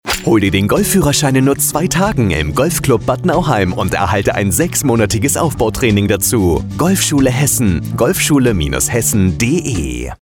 Funkspot_Golfschule-Hessen-10-Sek-1.mp3